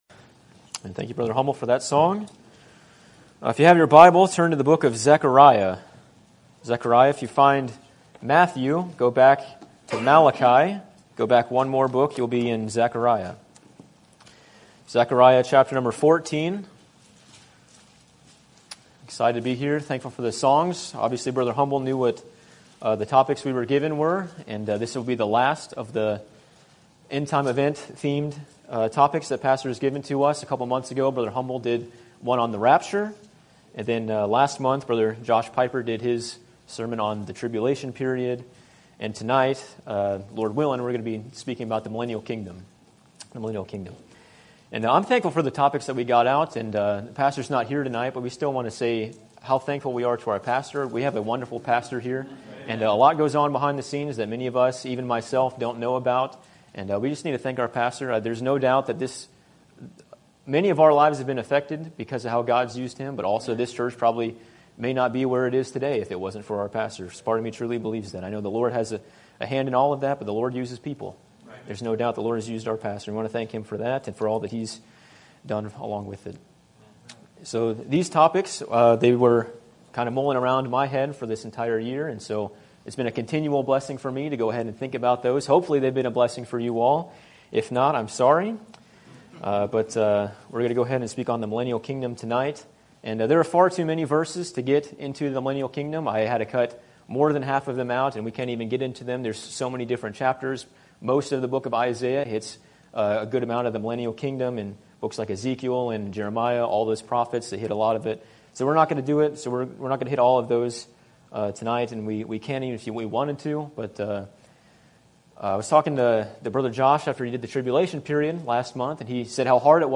Sermon Topic: General Sermon Type: Service Sermon Audio: Sermon download: Download (18.61 MB) Sermon Tags: Zechariah Doctrine Millennial King